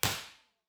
Room Impulse Response of a large recording studio
Description:  Large recording studio (52,000 ft³) at university.
With a T30 of about 0.6 sec, this room is not statistically reverberant, but it is live and diffuse.
File Type: Mono
IR_TP3_30ft_MedQ_Omni.wav